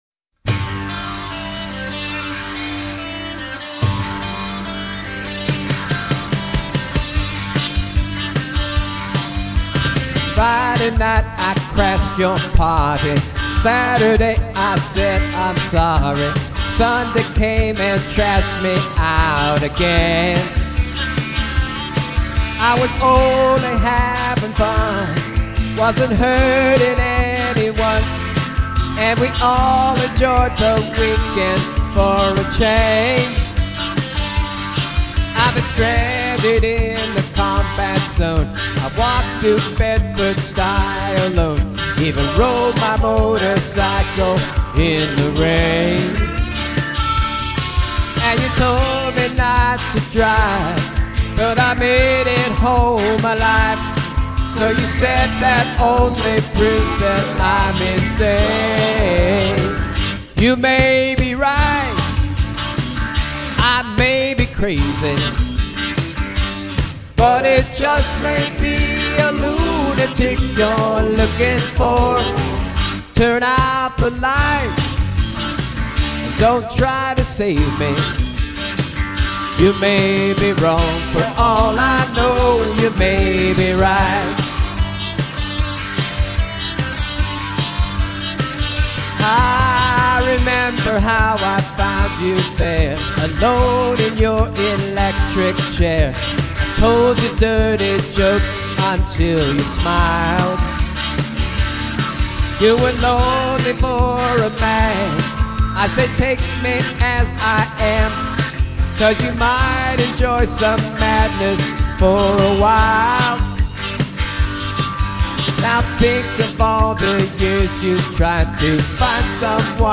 Rock, Pop & Soul